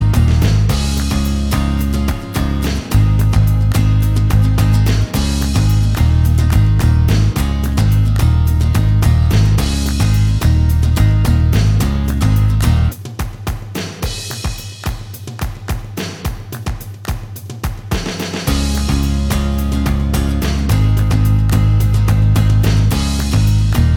Minus Lead Guitar Indie / Alternative 2:55 Buy £1.50